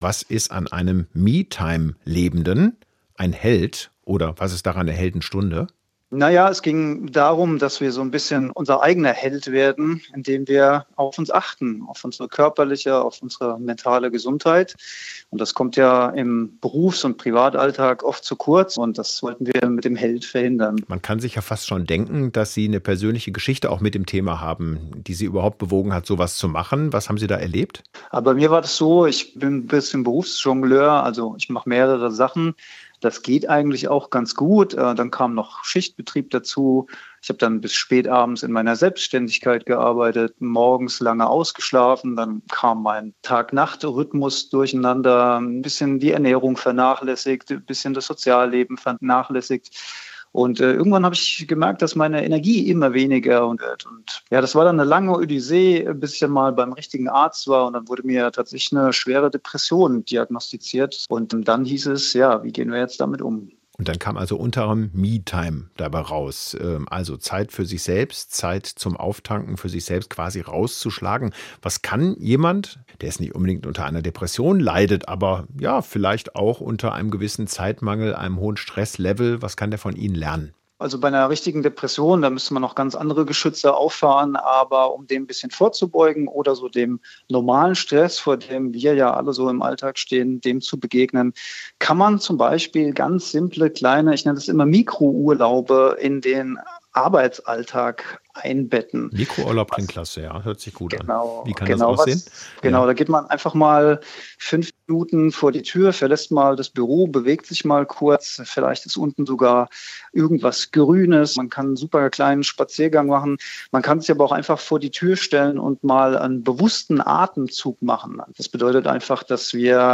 Mehr Interviews